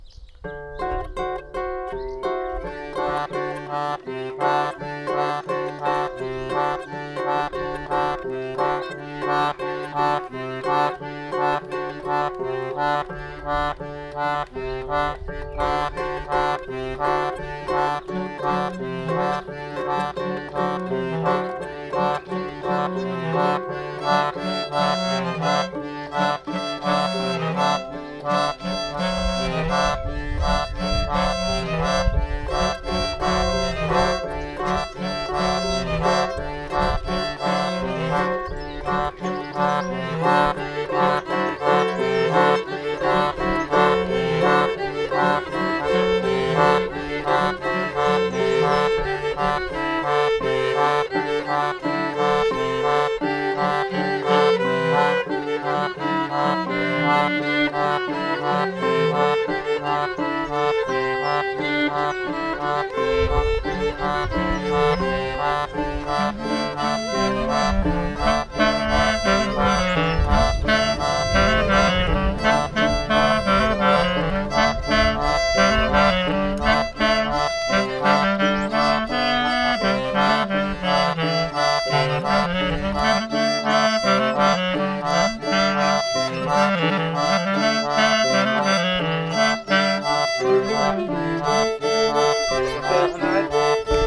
03_hanter_dro.mp3